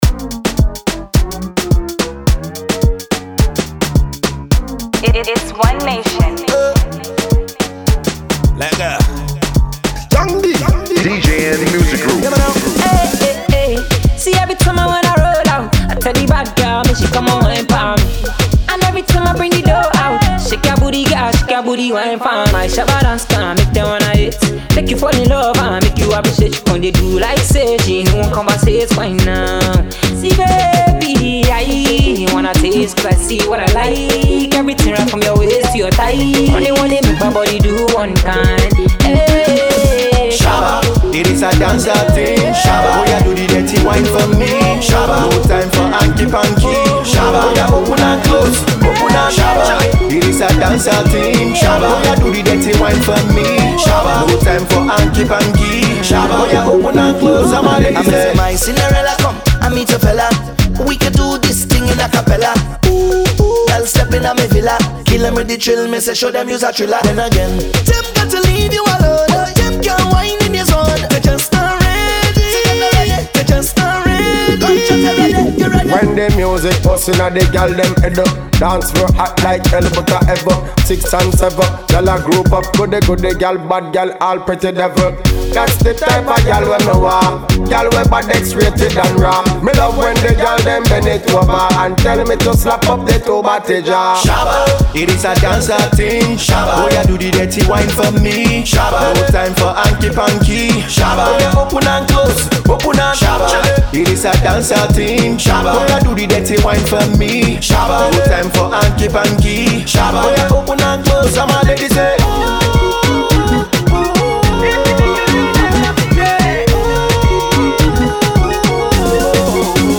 Dancehall meets Afrobeat.